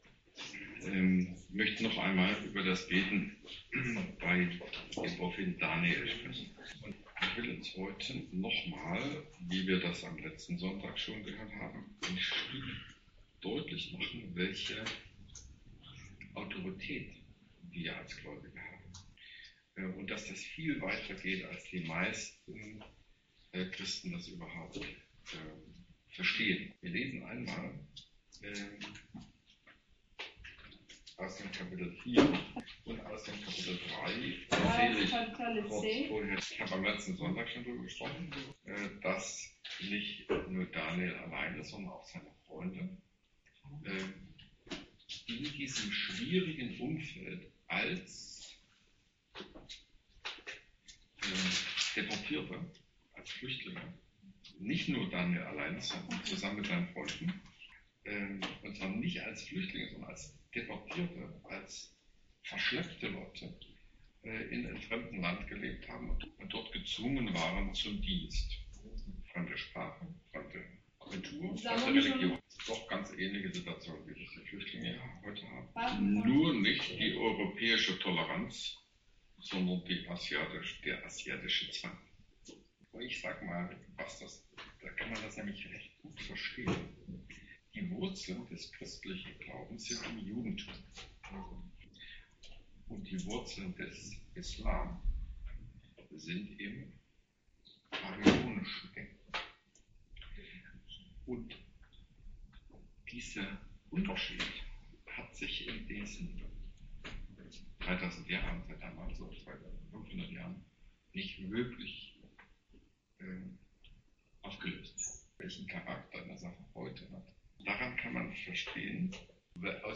Daniel 3+4, Predigt Thema: Gebet - Europäische Missionsgemeinschaft